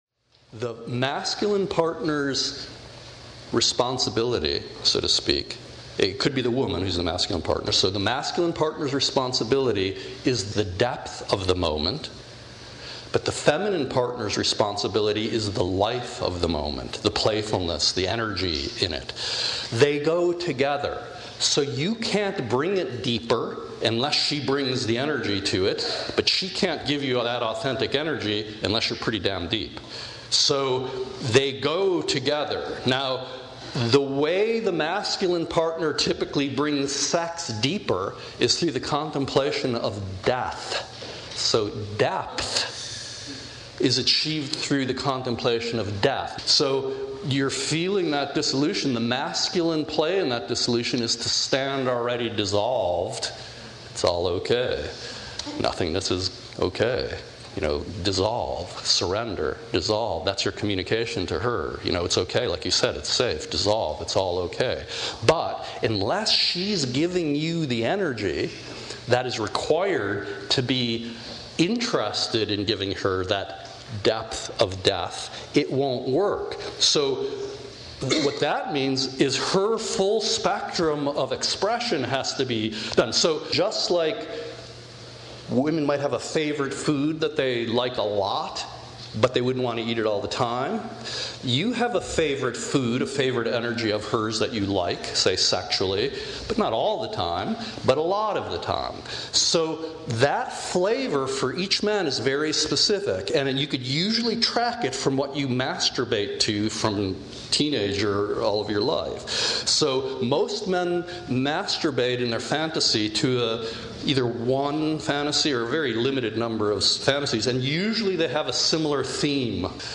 Complete Set (Part I, Part II & Part III) Recorded Live in San Francisco Year: April 2016 Total Length: 02:31:08 Format: Instant download mp3